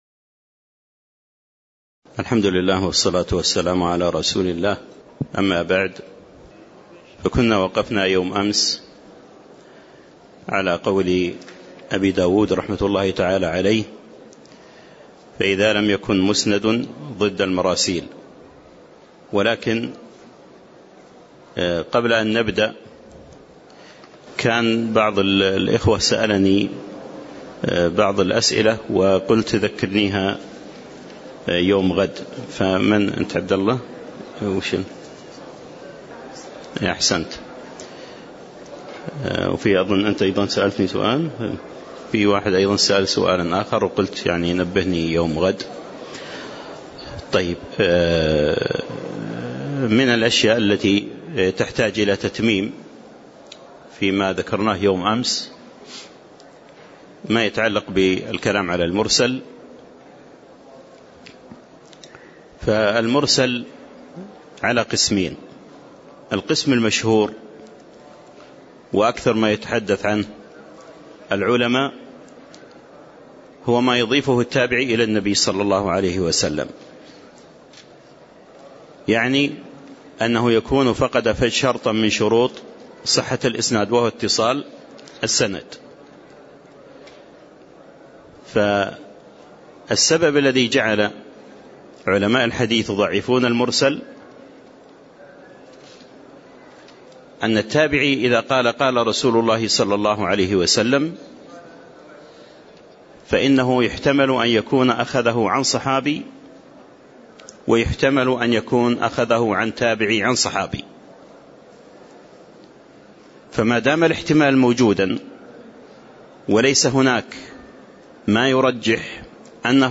تاريخ النشر ١٢ صفر ١٤٣٨ هـ المكان: المسجد النبوي الشيخ